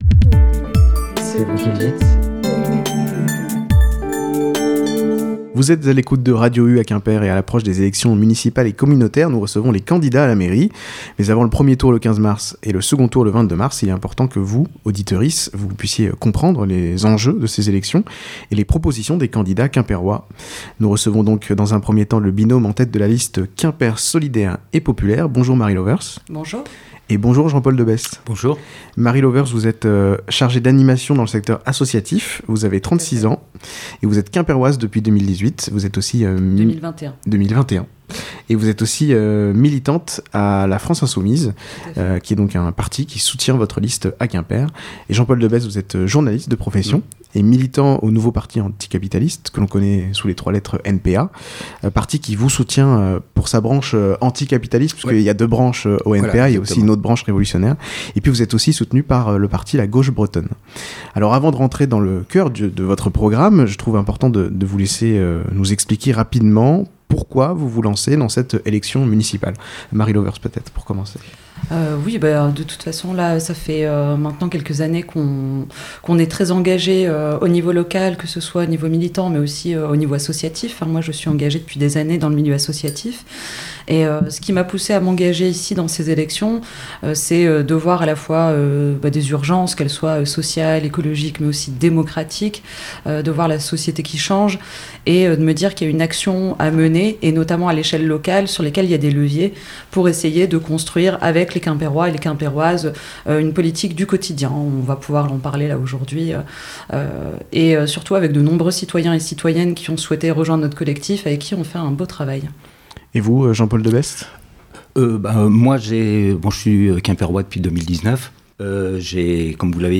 À l’approche des élections municipales et communautaires, Radio U reçoit les candidats à la mairie de Quimper.